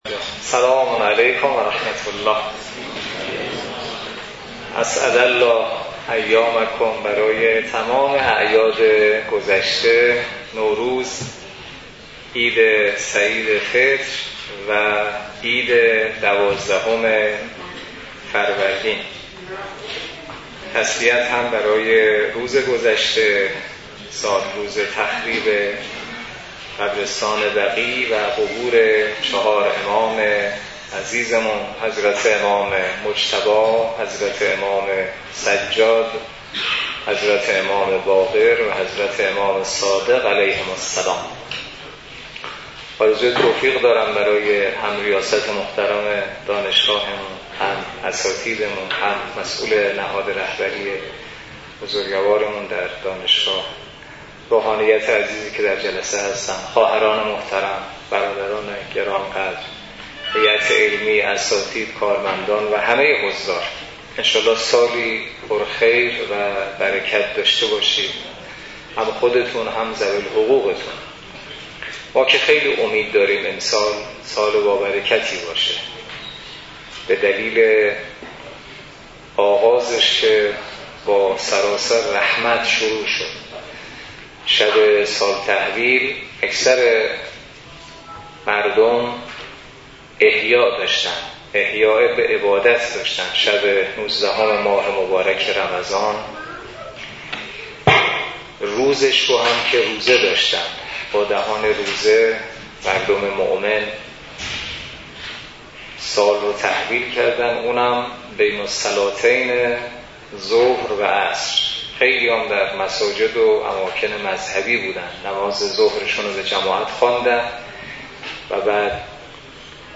برگزاری سی و چهارمین جلسه تفسیر نامه ۵۳ نهج البلاغه توسط نماینده محترم ولی فقیه و در دانشگاه کاشان
سی و چهارمین جلسه تفسیر نامه ۵۳ نهج البلاغه توسط حجت‌الاسلام والمسلمین حسینی نماینده محترم ولی فقیه و امام جمعه کاشان در دانشگاه کاشان برگزار گردید.